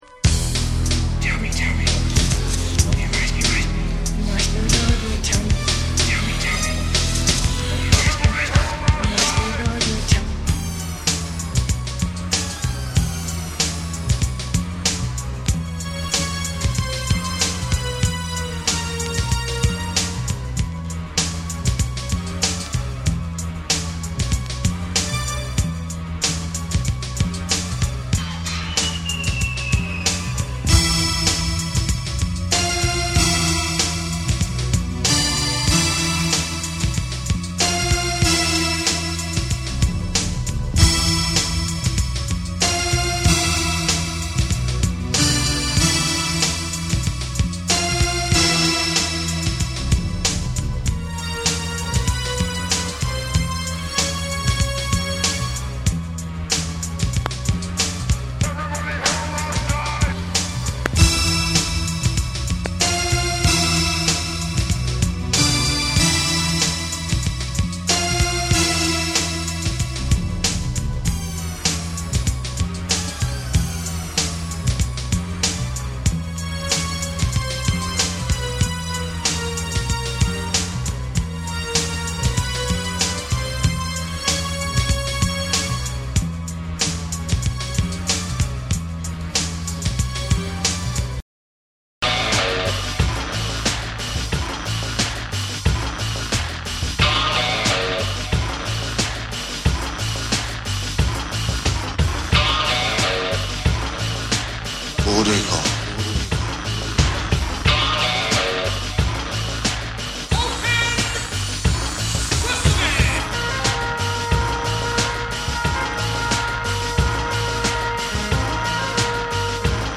パーカッシヴでスモーキーなブレイクビーツを披露する2。レイドバック感ある幻想的なダブ・ブレイクの4。
BREAKBEATS / REGGAE & DUB